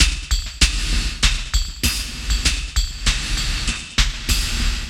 98WAGONFX1-R.wav